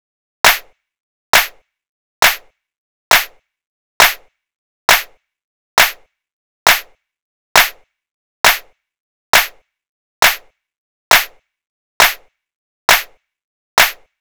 TI CK7 135  Clap 1.wav